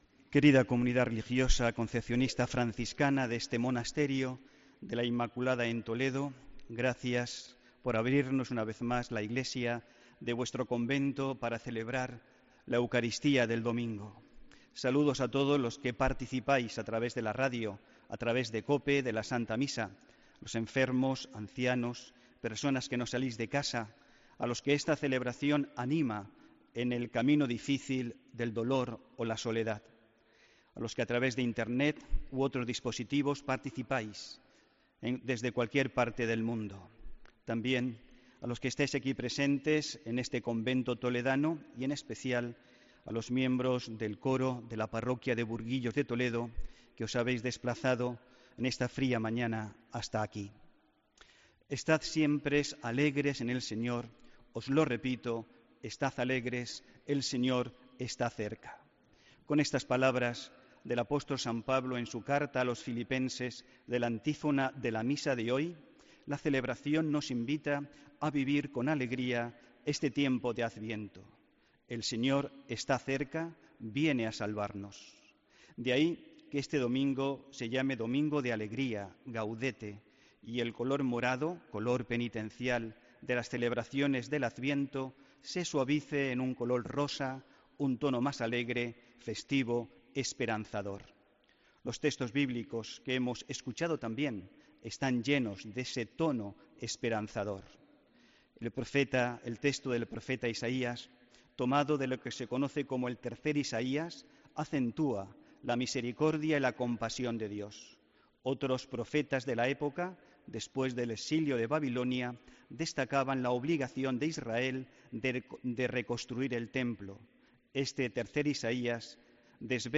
HOMILÍA 17 DICIEMBRE 2017